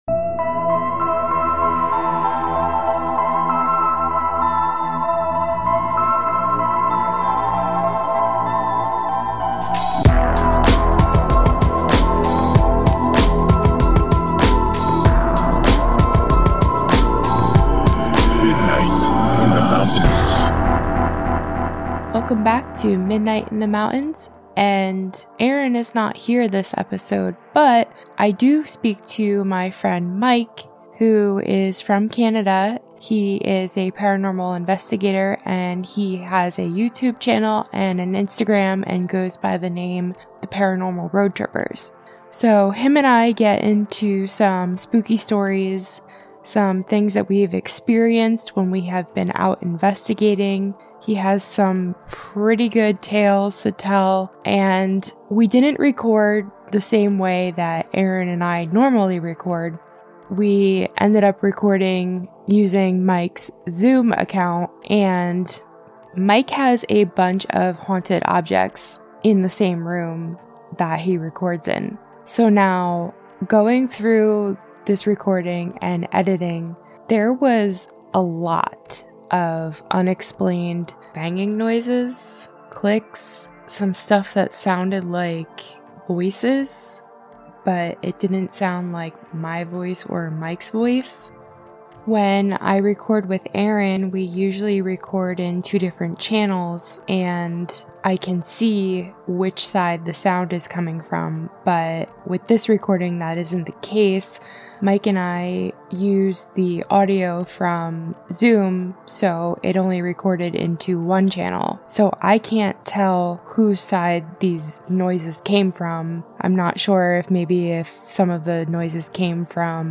During this recording there were many unexpected noises, including clicks and bangs and some possible disembodied voices. Some of those audio anomalies have been clipped and placed at the end of the episode.